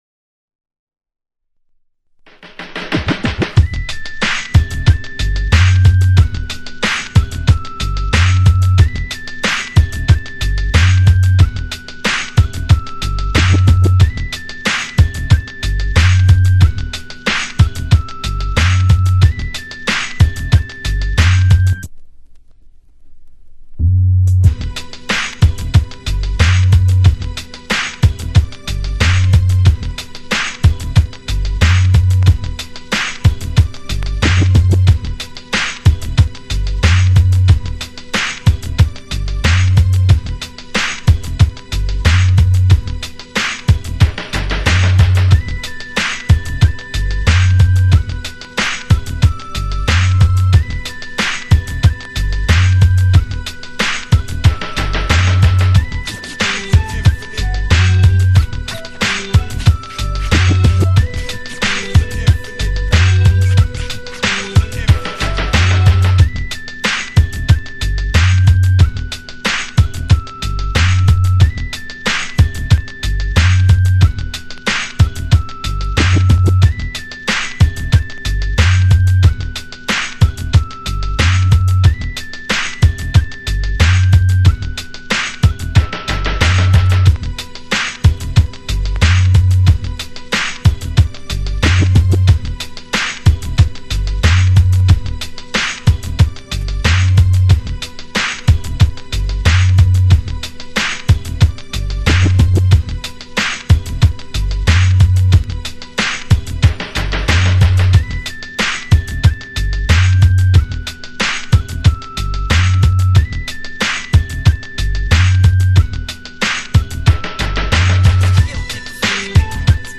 This is the official instrumental of "